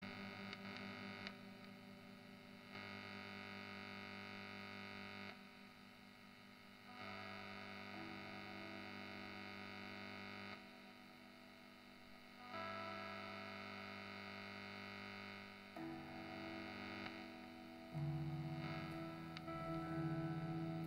Klar rauschen auch normale Amps bei hohen Gain/Volume Settings, aber das Brummen geht doch schon auf die Nerven.
Hab mal was hochgeladen, wo man das hören kann... Wenn die Finger auf den Saiten sind, ist das Brummen weg.